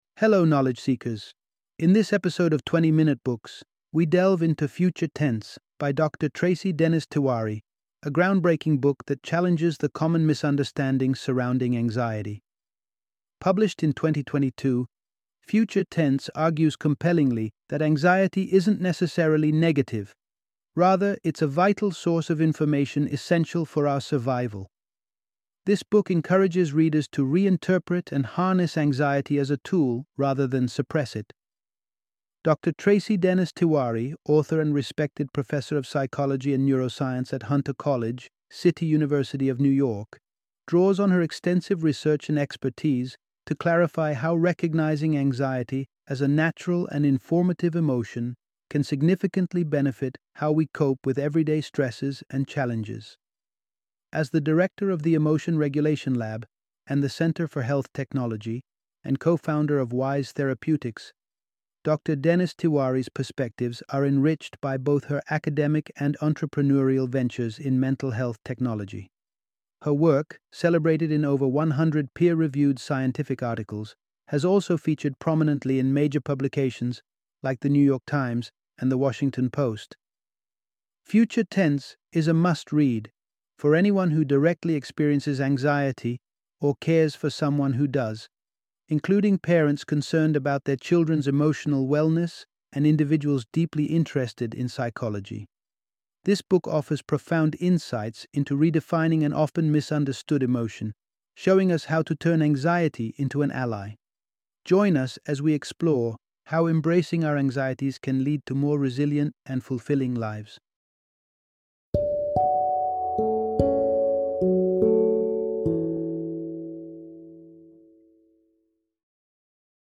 Future Tense - Audiobook Summary